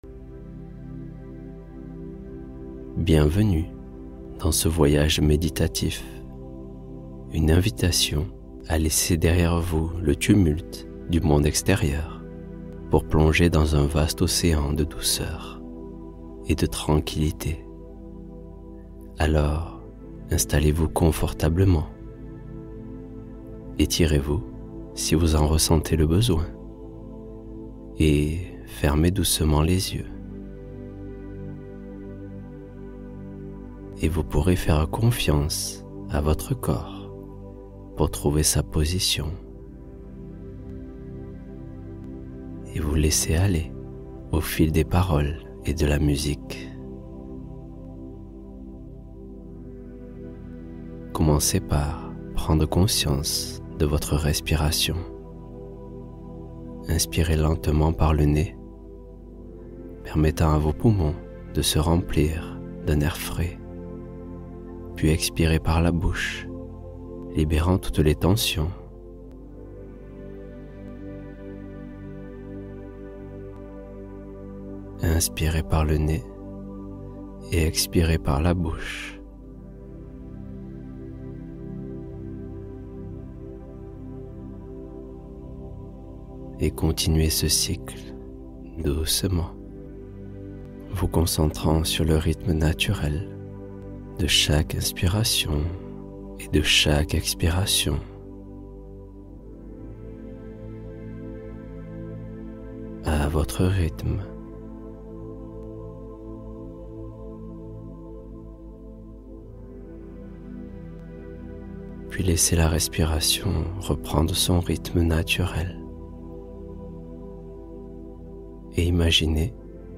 Histoire du soir — Les deux princes et la montgolfière